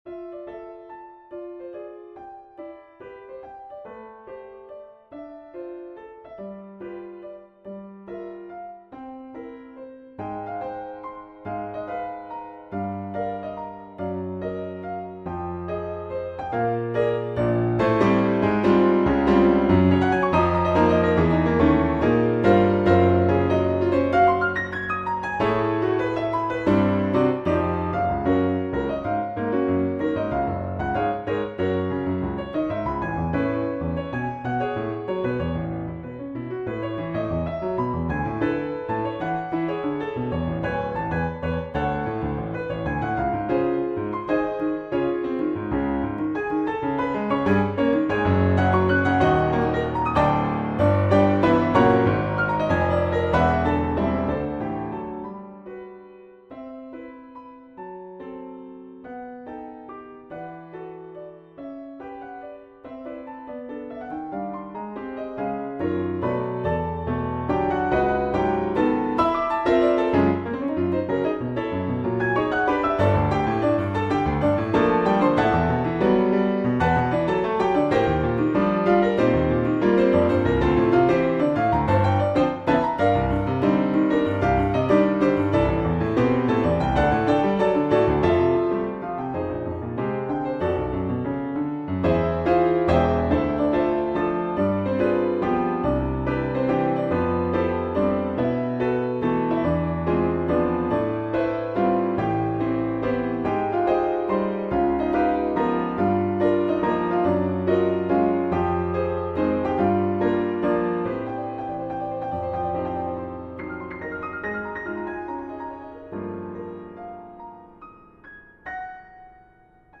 solo piano arrangement